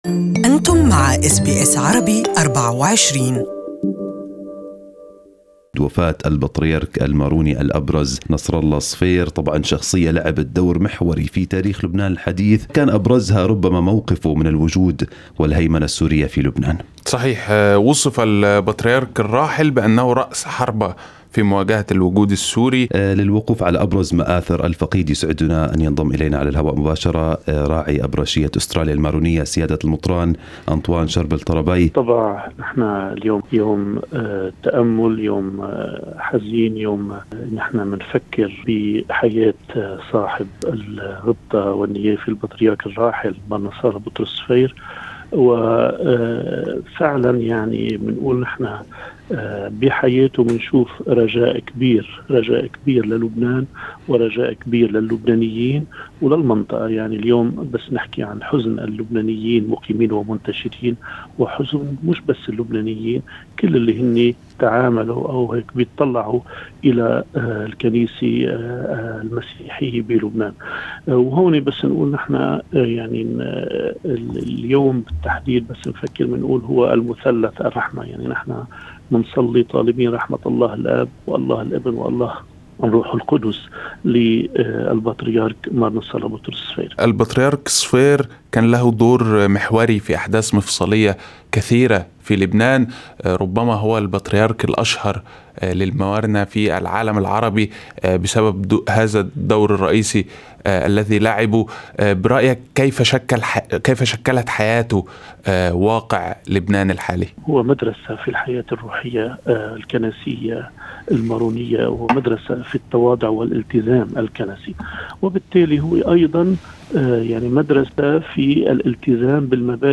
SBS Arabic24 interviewed the Maronite Bishop of Australia, Antoine-Sharbel Tarabay to commemorate Patriarch Nasrallah Sfeir who passed away at the age of 99, leaving behind a rich legacy of national commitment to Lebanon and its people, Christians and Muslims alike.